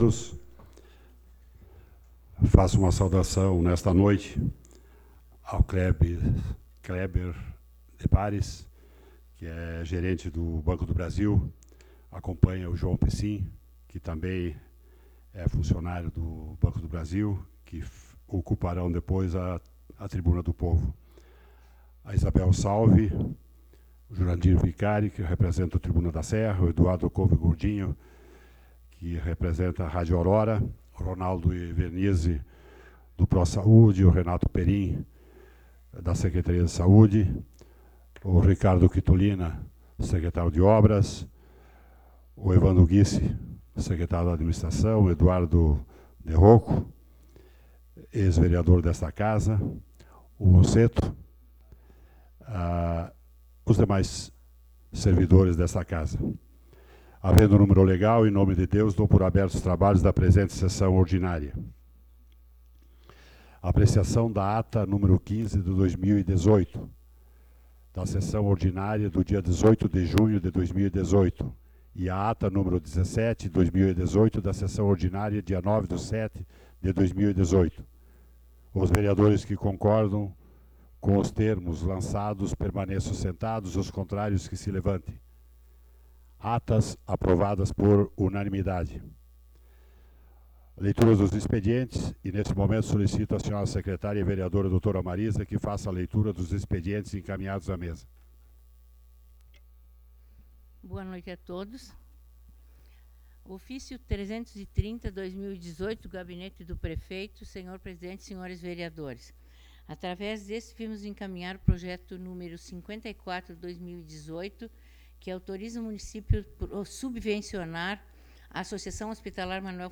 Sessão Ordinária do dia 16 de Julho de 2018